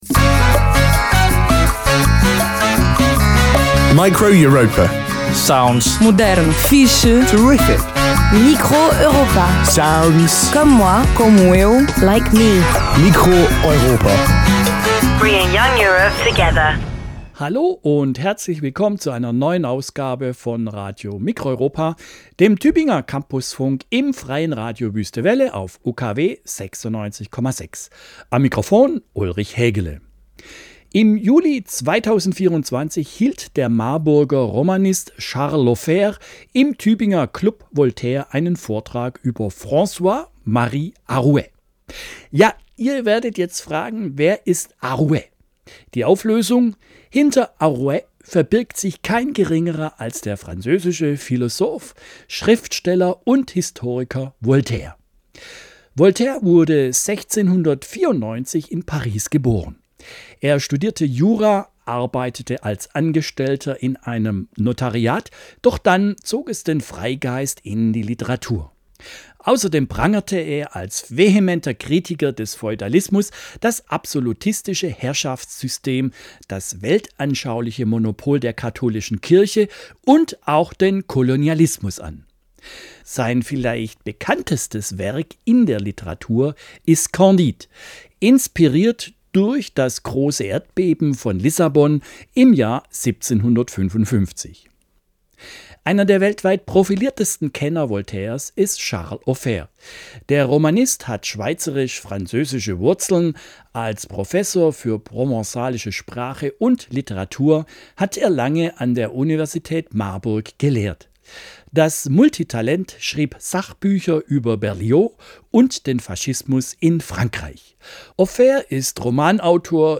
Es war Markttag, was im Hintergrund gut zu hören ist.
Form: Live-Aufzeichnung, geschnitten